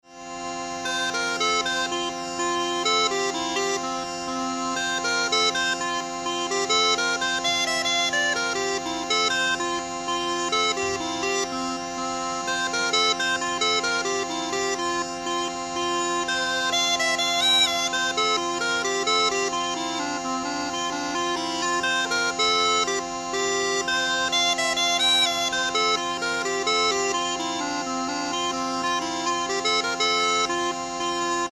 Ashington Folk Club - Spotlight 20 October 2005
Northumbrian piper  was next.